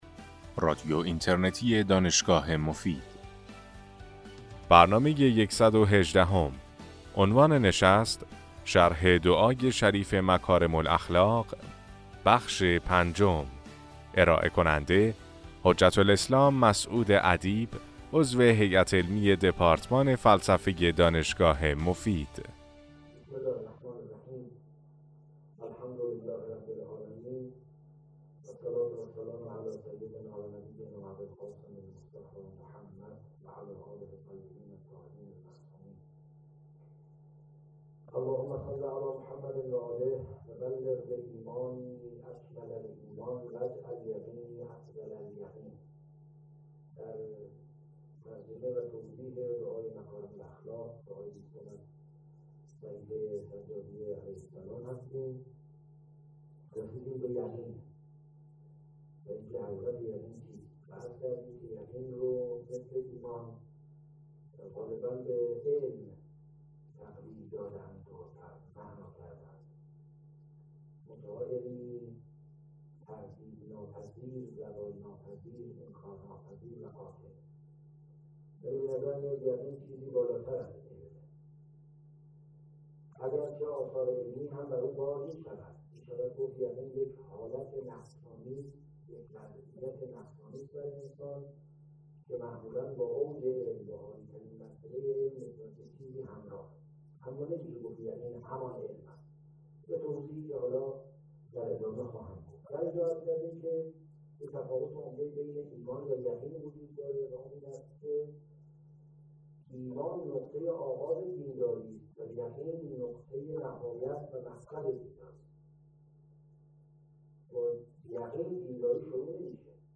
در این سلسله سخنرانی که در ماه رمضان سال 1395 ایراد شده است به شرح و تفسیر معانی بلند دعای مکارم الاخلاق (دعای بیستم صحیفه سجادیه) می پردازند.